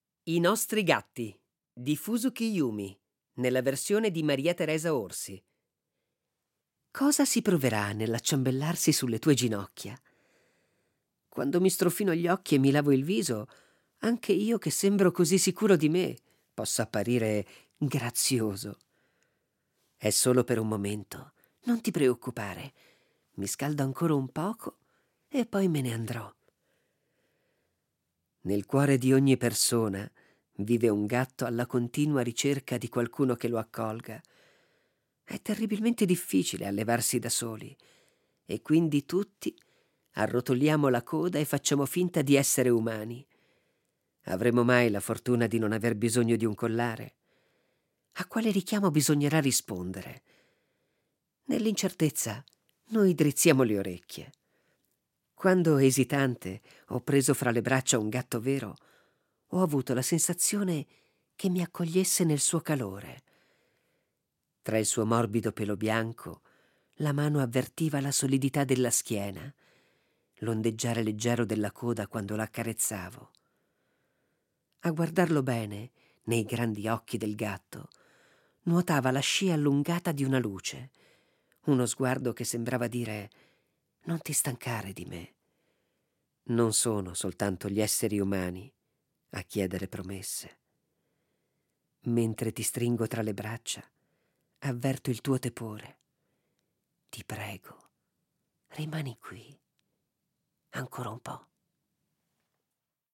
Con enorme piacere, Colpo di poesia dà loro voce per alcune settimane attraverso le letture